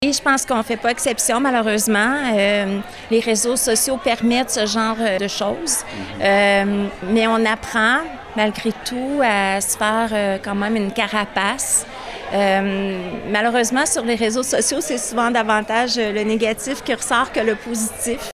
Pour l’occasion, les médias étaient invités au Manoir Bécancour.
Lors de son discours devant une soixantaine de personnes, elle a présenté ses orientations, dont miser sur la croissance humaine et durable, améliorer la qualité de vie des citoyens et dynamiser l’économie locale.